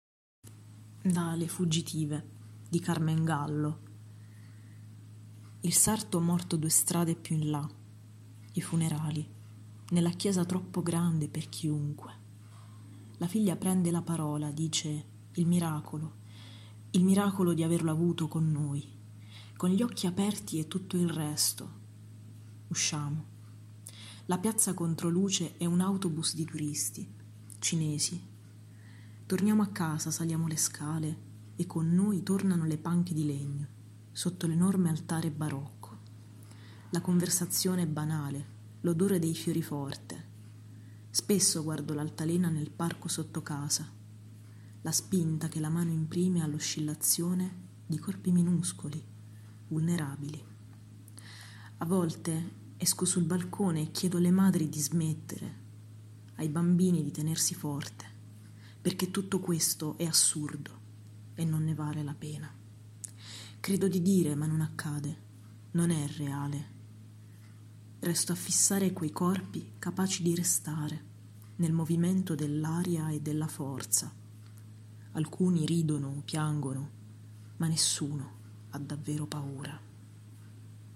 Lettura